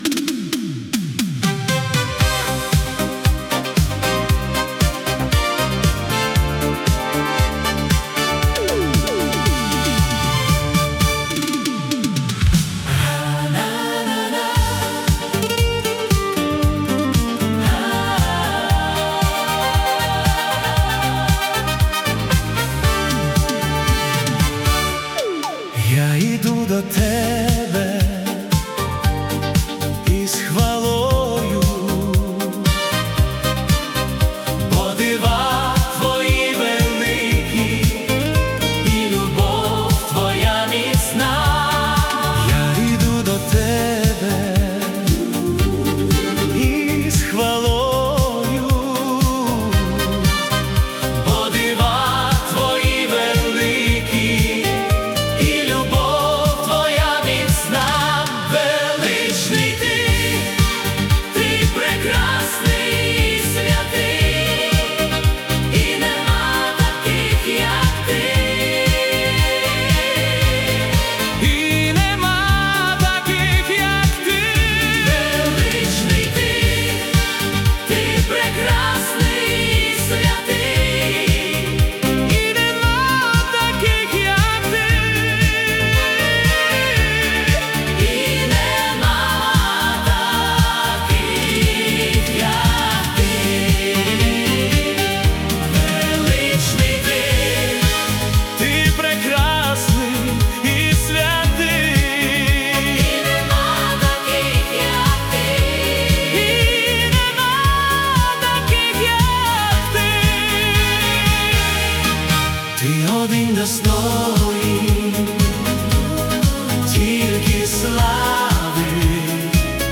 Мелодійна пісня у стилі українських ВІА.
Гімн прослави у золотих барвах ВІА 70-х 🌅